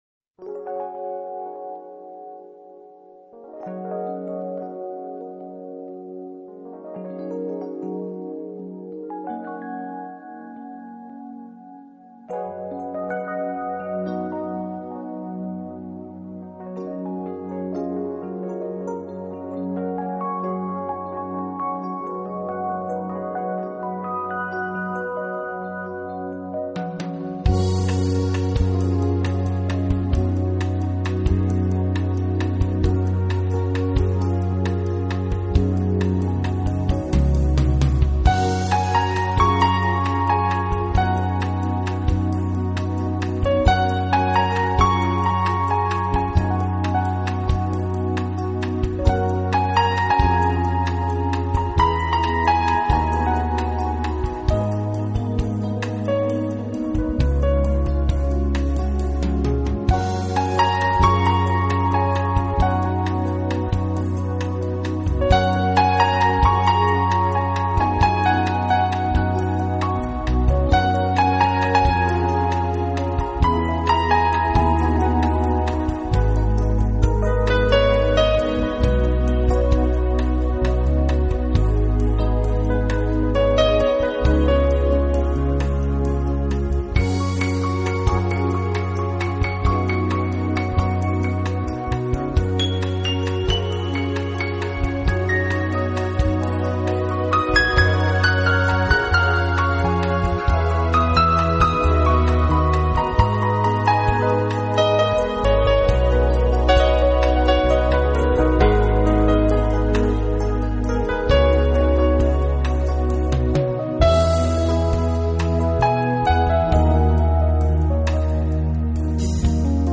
【钢琴纯乐】